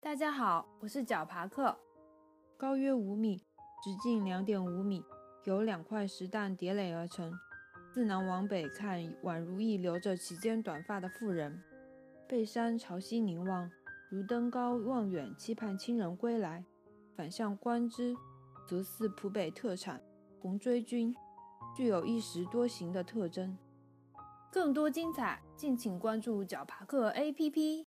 石蛋【望夫石（石蘑菇）】----- fin 解说词: 高约5m，直径2.5m，由两块石蛋叠垒而成。